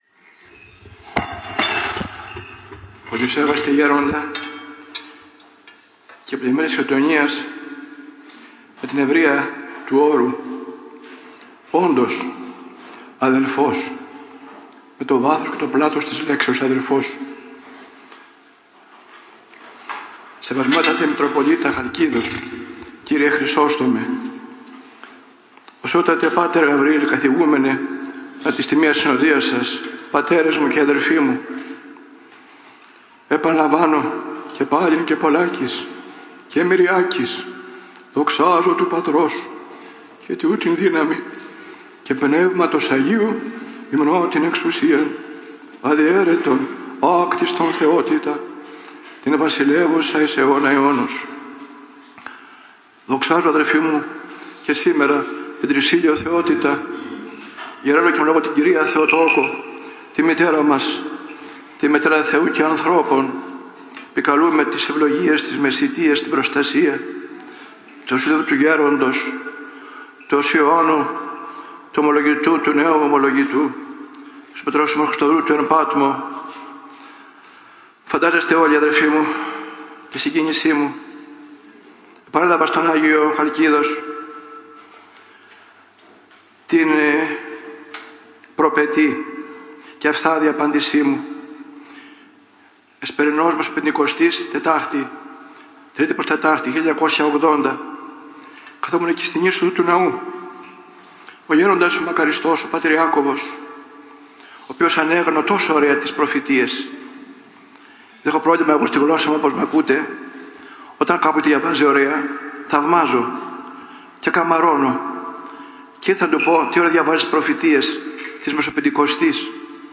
Ομιλία Μακαριστού Κεφαλληνίας κ. Φωκά στην Ιερά Μονή Οσίου Δαυΐδ (ΗΧΗΤΙΚΟ)
Ομιλία του Μακαριστού Κεφαλληνίας κ. Γερασίμου